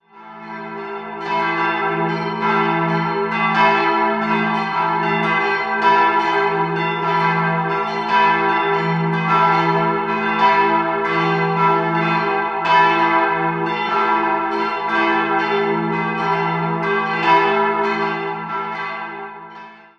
Beschreibung der Glocken
4-stimmiges Geläute: e'-g'-f''-h''